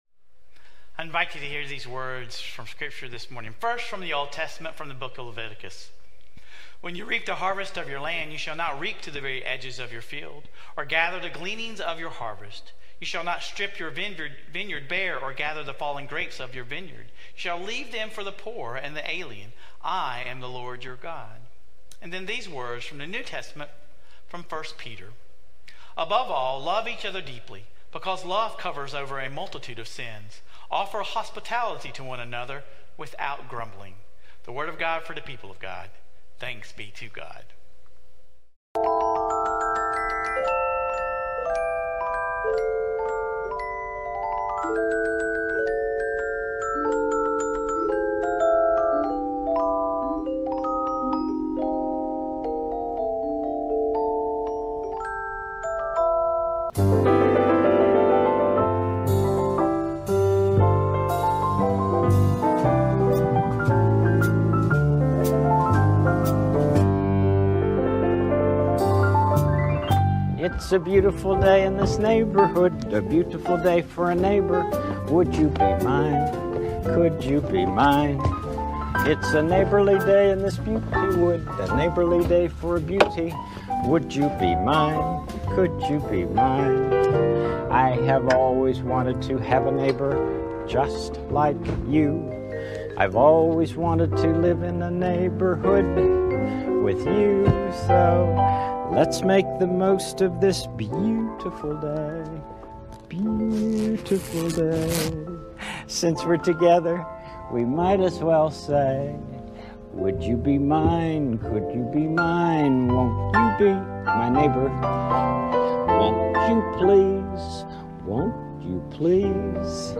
Sermon Reflections: How can you create margin in your life to better love your neighbors?